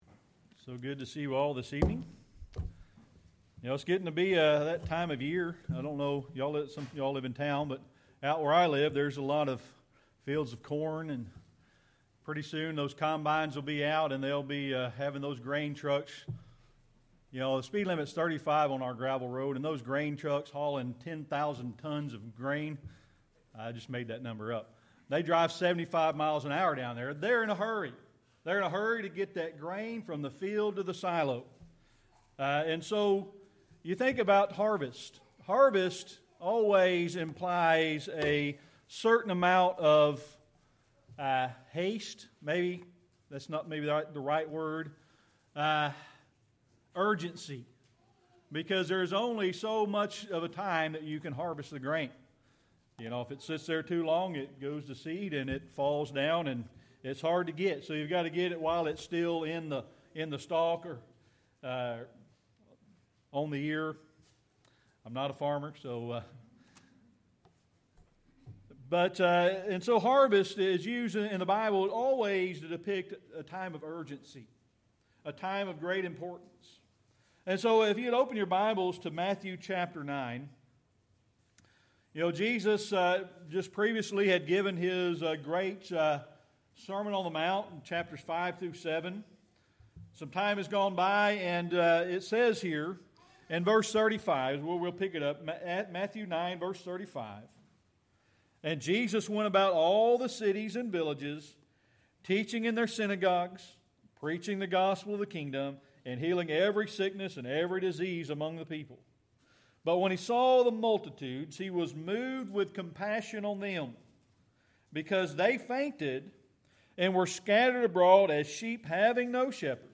Series: Sermon Archives
Matthew 9:36-38 Service Type: Sunday Evening Worship It's getting to be the time of year when fields of corn are ready to be harvested.